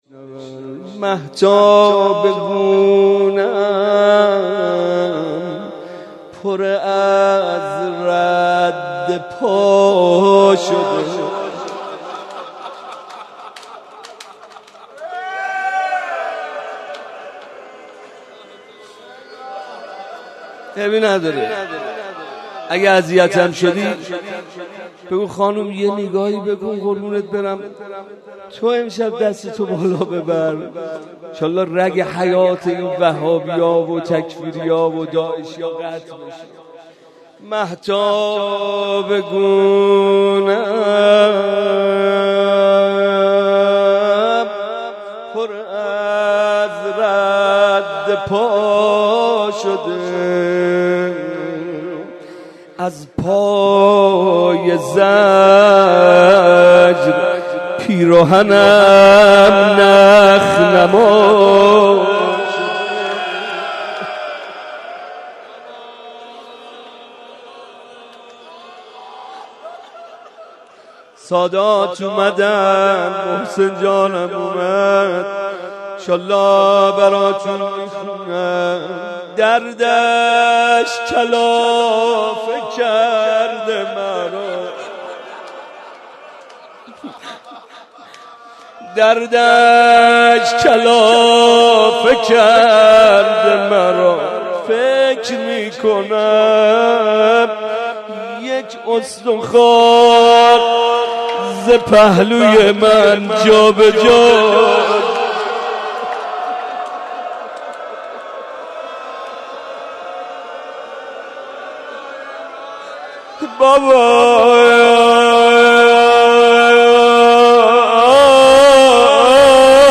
بخش اول - مناجات دریافت بخش دوم